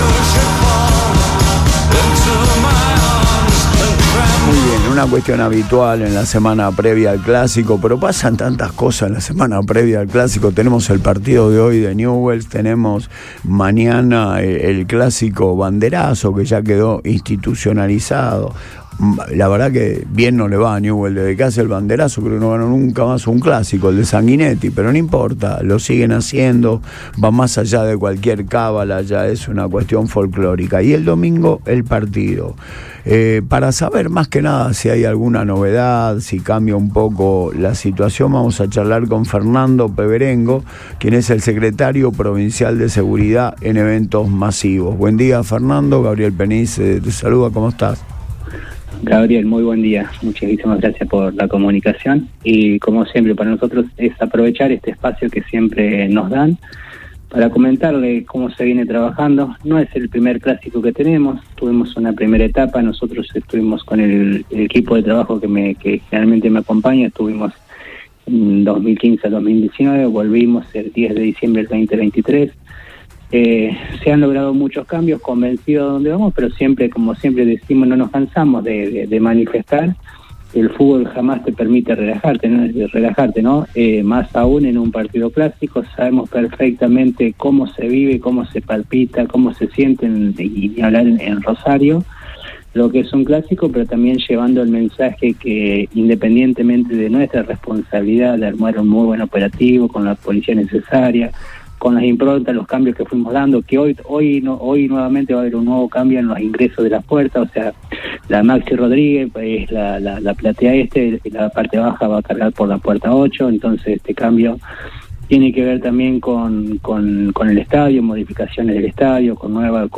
En diálogo con Radio Boing, el funcionario adelantó que las puertas del Coloso se abrirán tres horas antes y explicó que la llegada de Lucas Bernardi al banco leproso ayuda a descomprimir el clima social.